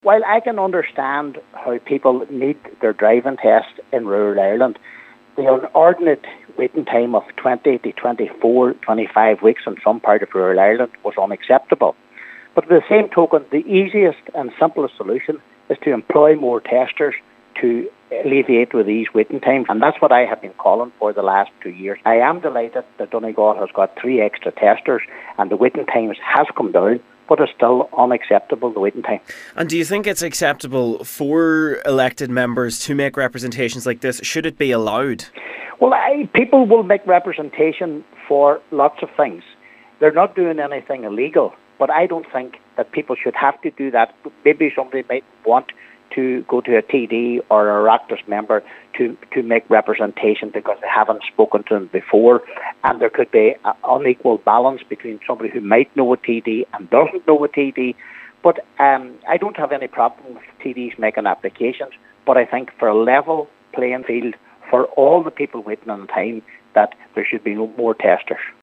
He says learner drivers shouldn’t have to feel that contacting a TD or Oireachtas member to secure a driving test is the only option: